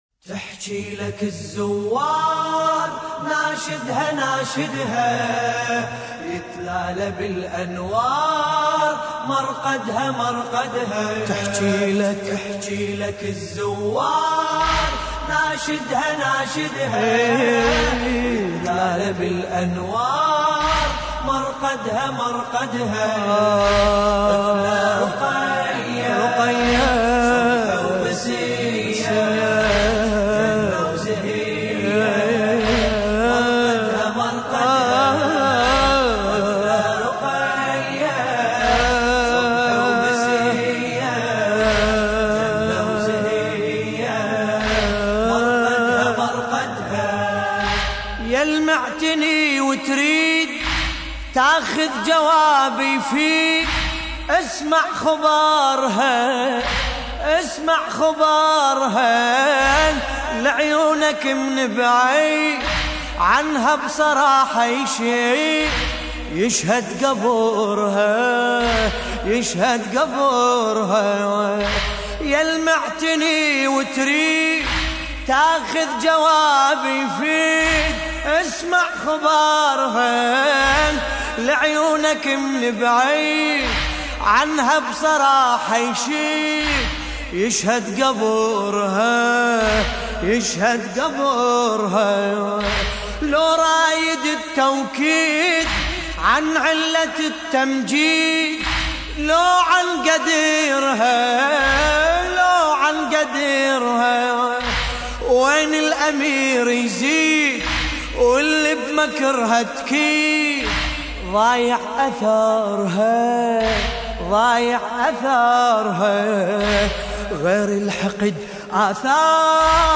تحميل : تحكيلك الزوار ناشدها ناشدها يتلاله بالانوار مرقدها مرقدها طفلة رقية / الرادود باسم الكربلائي / اللطميات الحسينية / موقع يا حسين